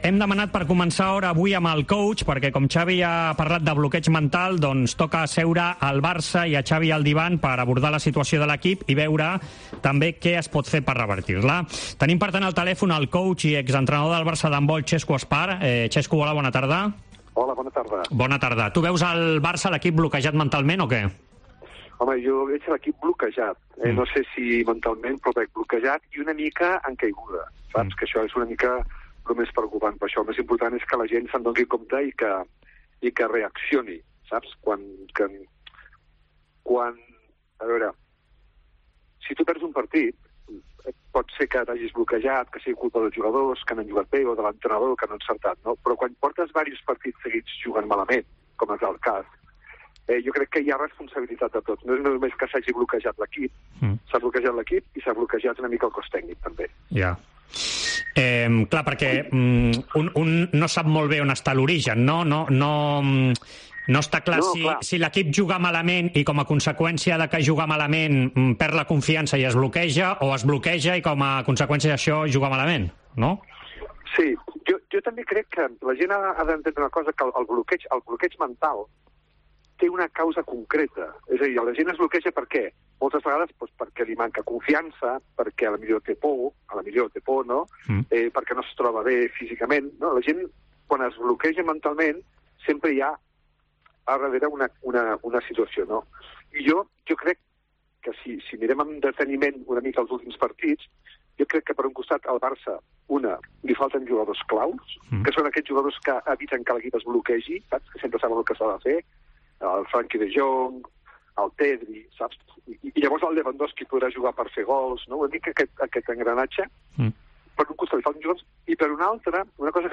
AUDIO: Parlem amb l'exentrenador del Barça d'handbol, expert en alt rendiment.